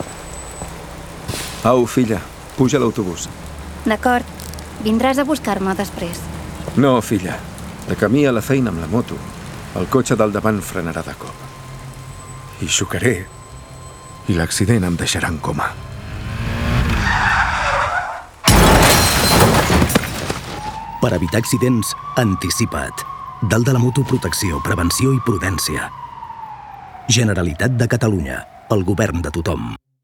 Per evitar accidents anticipa't falca 1